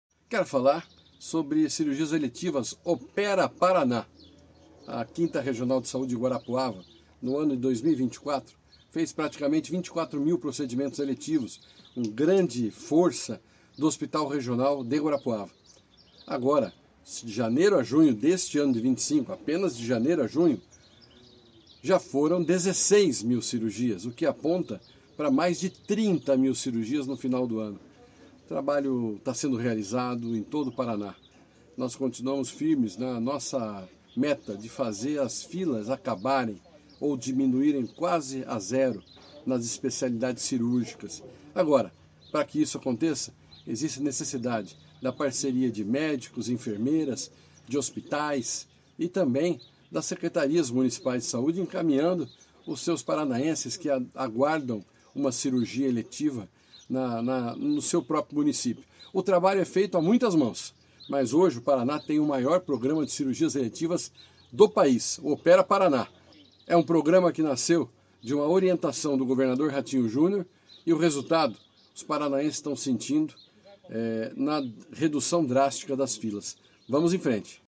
Sonora do secretário da Saúde, Beto Preto, sobre o programa Opera Paraná